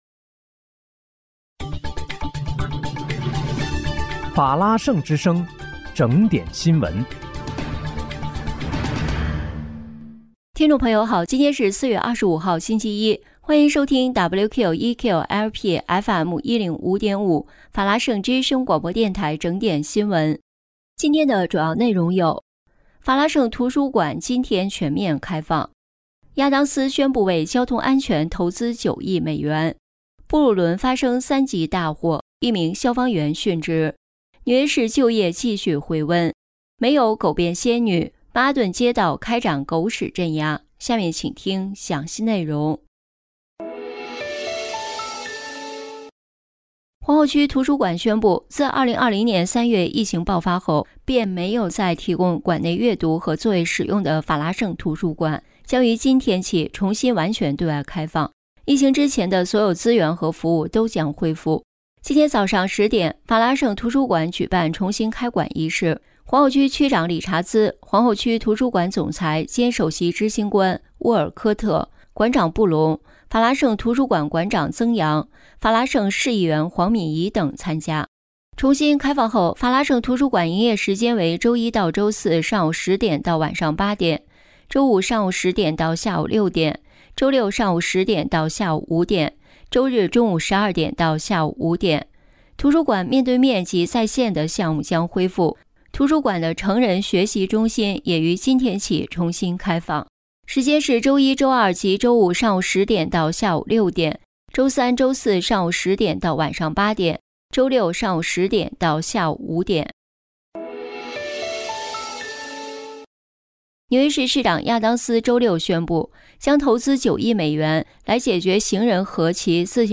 4月25日（星期一）纽约整点新闻